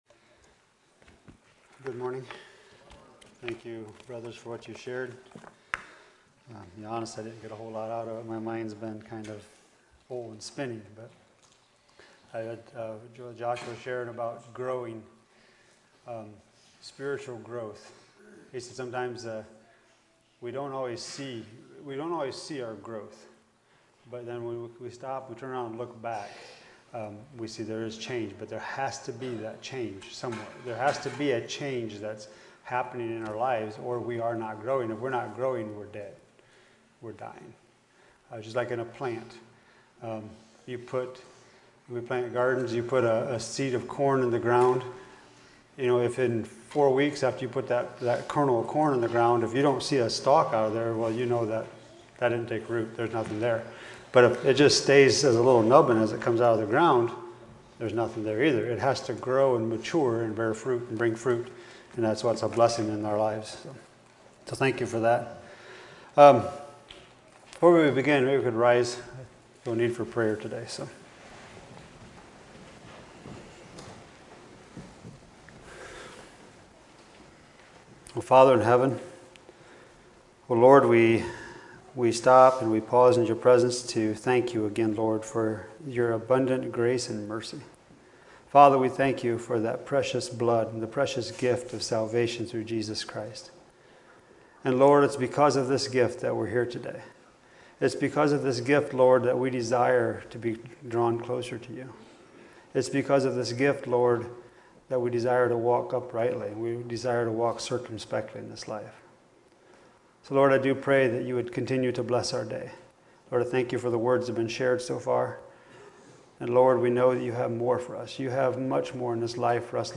2024 Vision Meetings , Sunday Morning Sermons